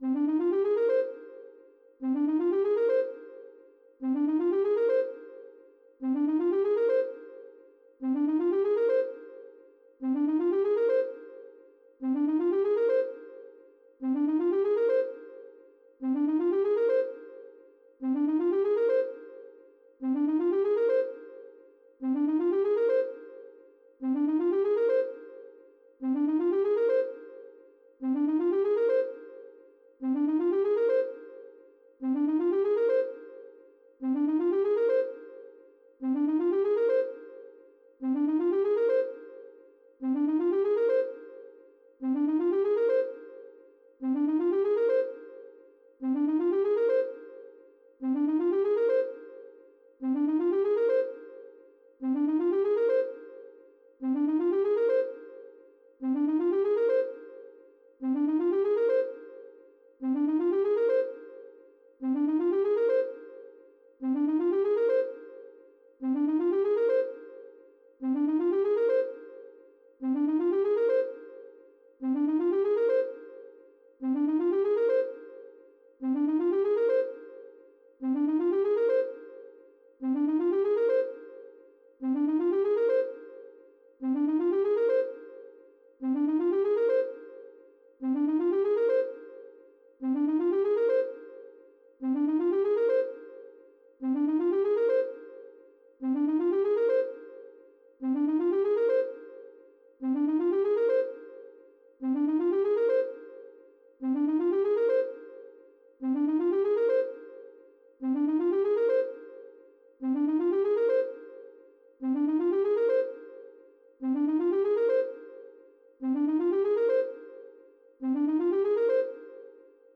Pop Sad 02:00